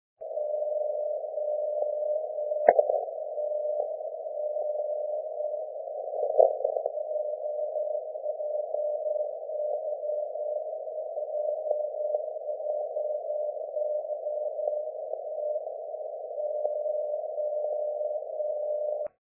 It's that time of year ... propagation peak near sunrise coincides with JJY's CW ID at 11:45:40 Z.
Short MP3 of JJY ID at